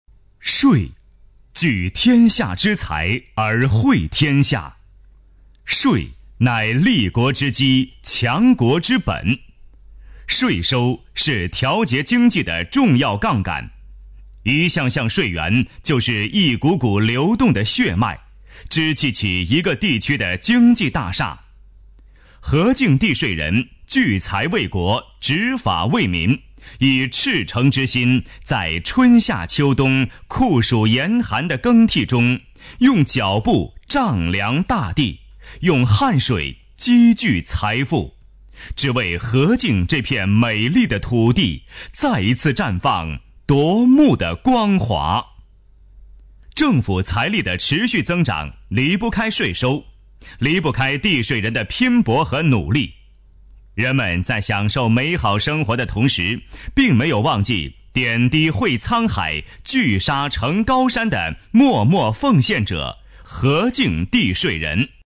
男国9_专题_政府_地税片解说_亲切.mp3